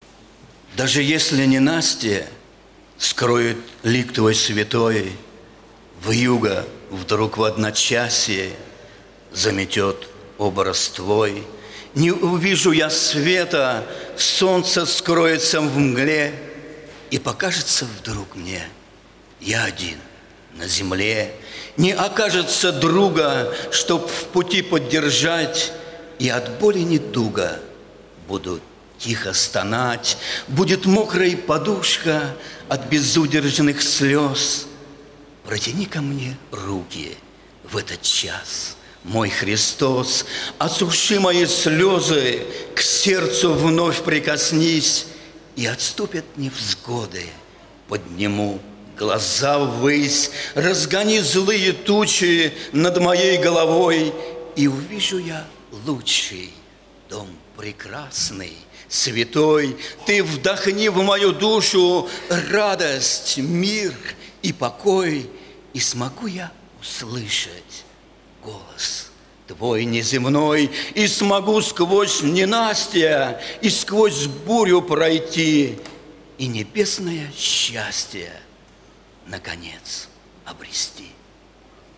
Богослужение 08.09.2019
Стихотворение